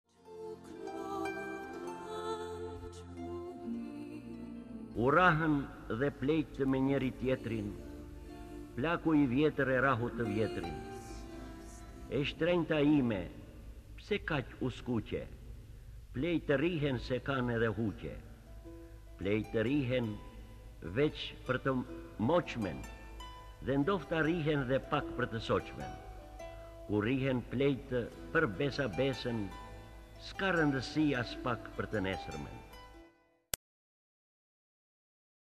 D. AGOLLI - U RRAHËN PLEQTË Lexuar nga D. Agolli KTHEHU...